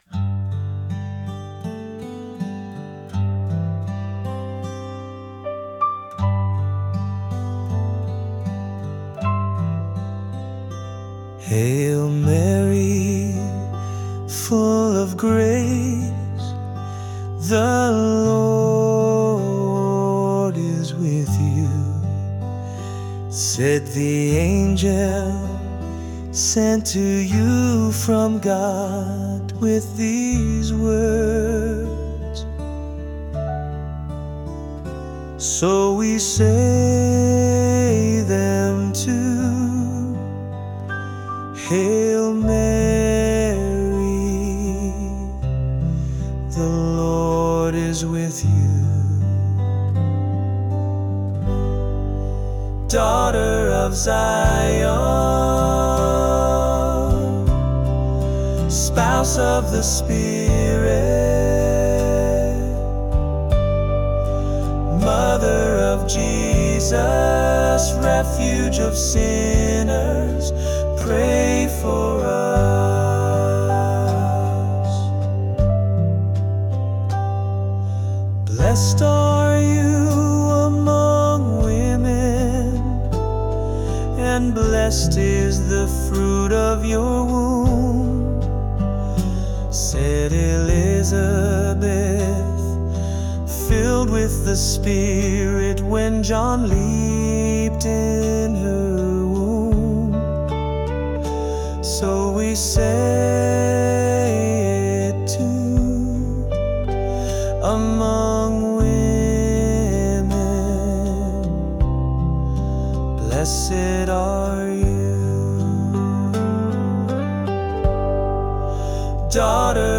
Mainstream